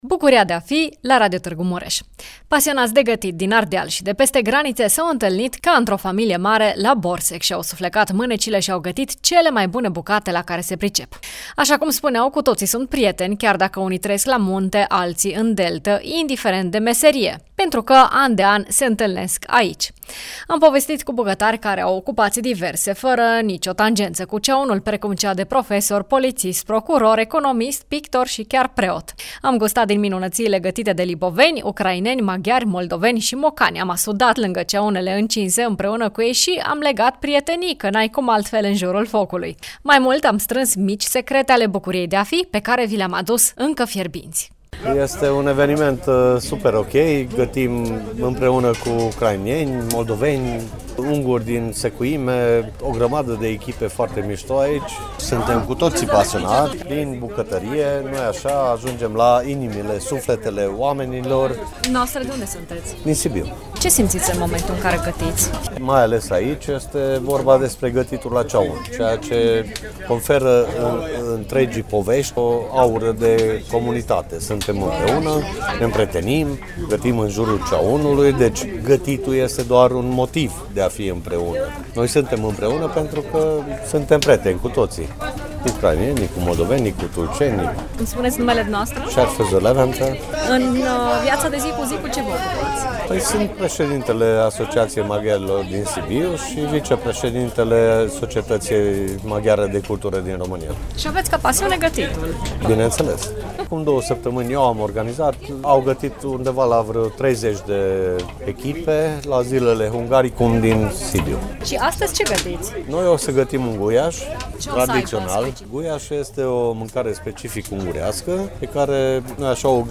18-iul-BDF-Demonstratie-de-gatit-Borsec.mp3